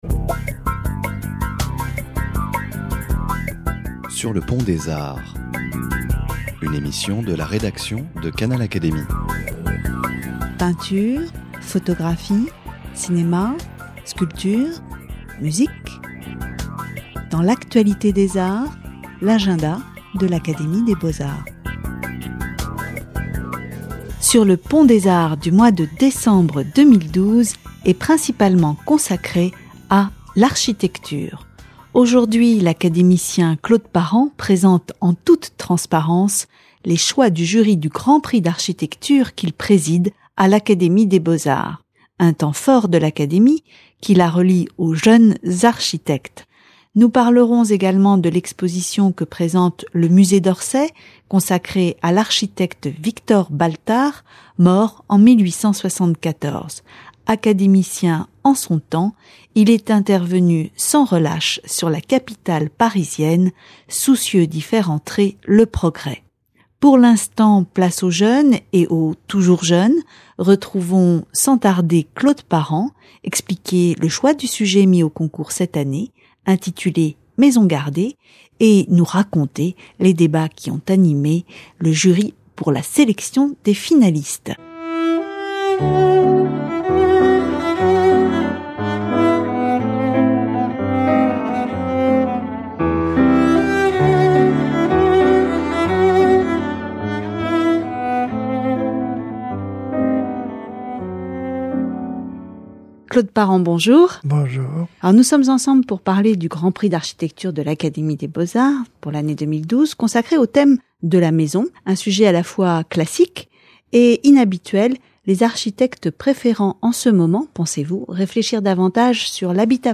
Sur le Pont des arts du mois de décembre 2012 est principalement consacré à l’architecture. Aujourd’hui, l’académicien Claude Parent, présente en toute transparence, les choix du jury du Grand Prix d’architecture qu’il préside à l’Académie des beaux-arts.